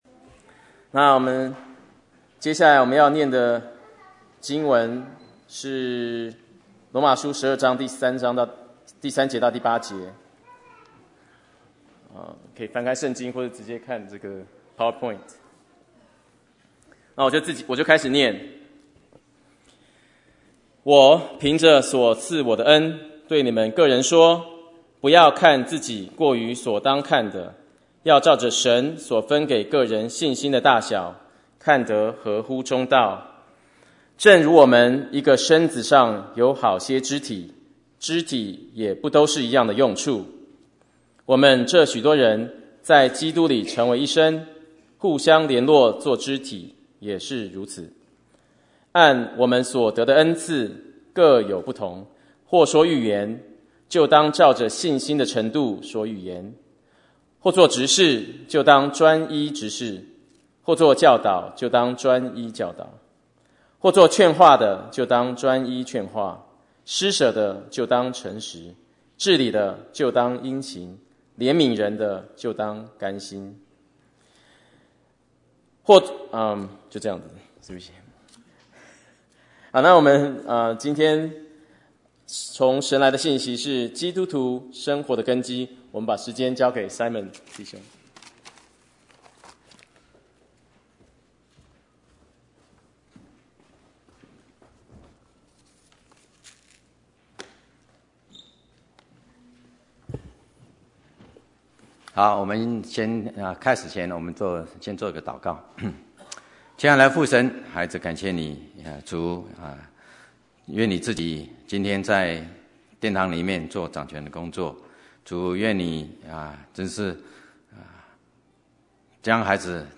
Bible Text: 羅馬書 12:3-8 | Preacher: